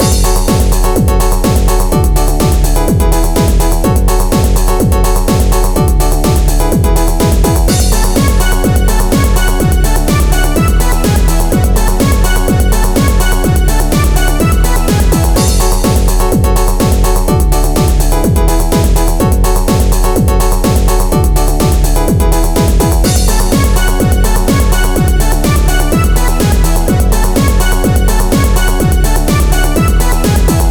Tracked in Renoise in 2025